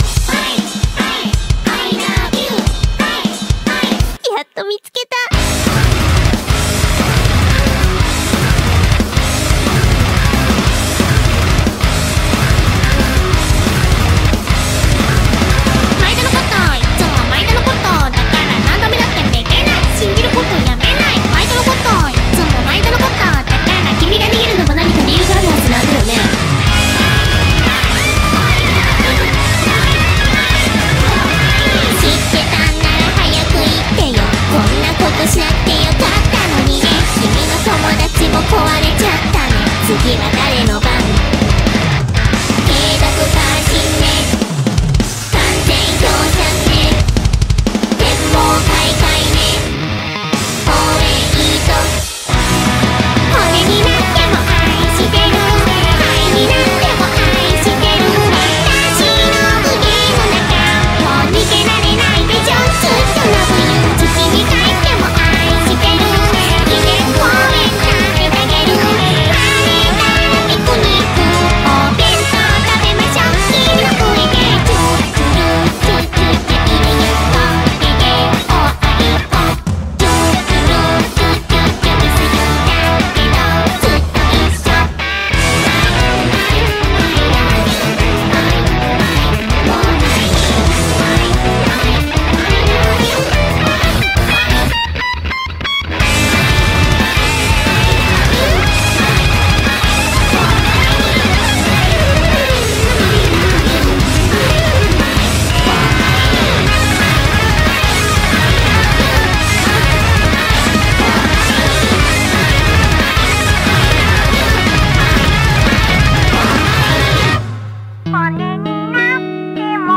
BPM180
Audio QualityPerfect (High Quality)
UWU METAL